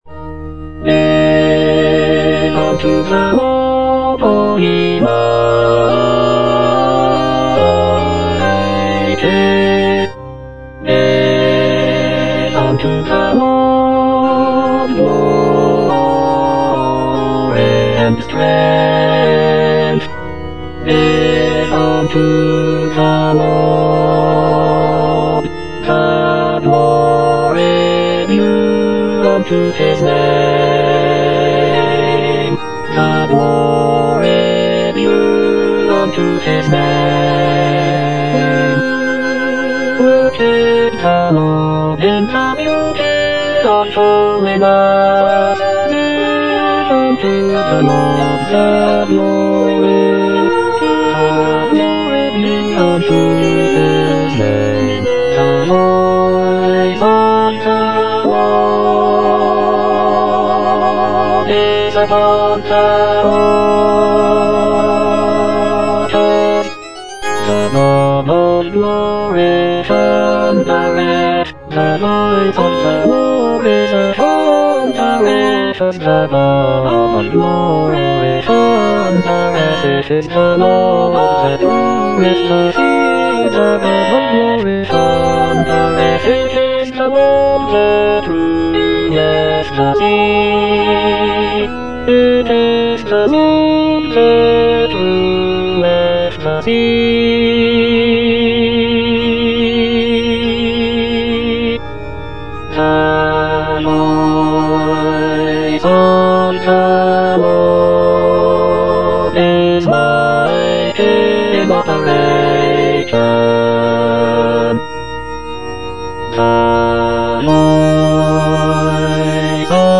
E. ELGAR - GIVE UNTO THE LORD Bass II (Emphasised voice and other voices) Ads stop: auto-stop
"Give unto the Lord" is a sacred choral work composed by Edward Elgar in 1914.